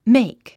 発音
méik　メェイク